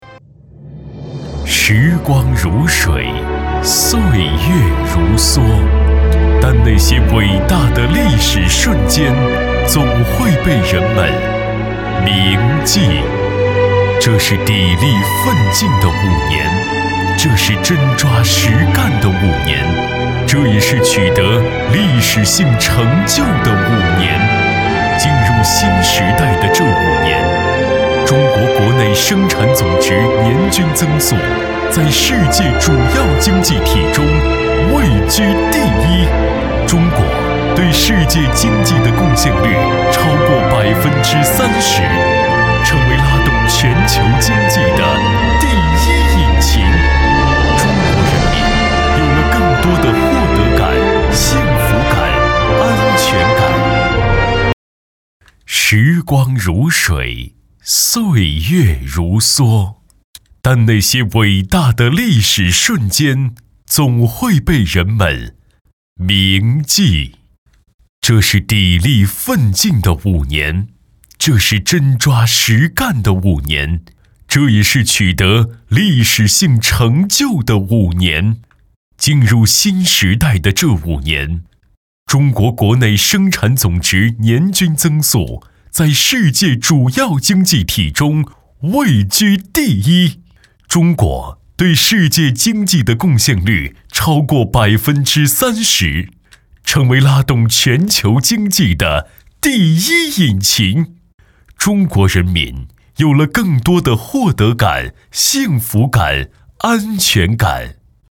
宣传片/专题片